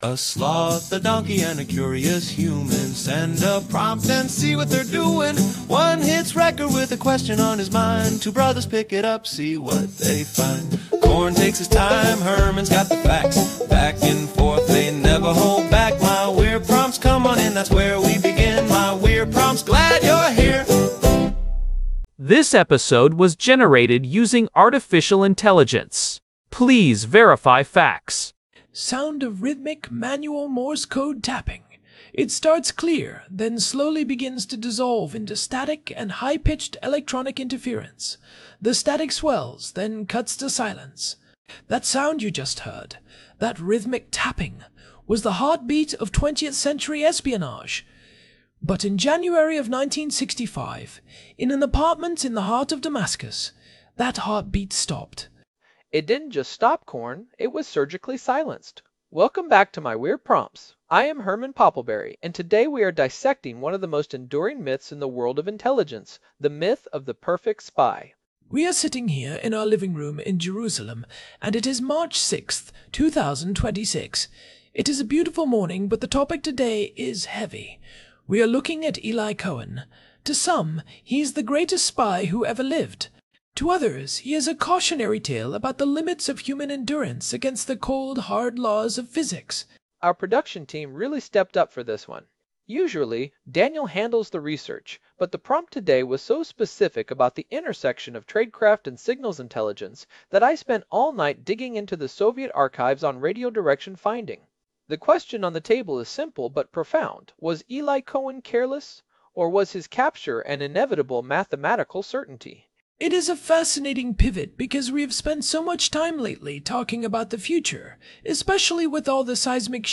H (Sound of rhythmic, manual Morse code tapping. It starts clear, then slowly begins to dissolve into static and high-pitched electronic interference. The static swells, then cuts to silence.)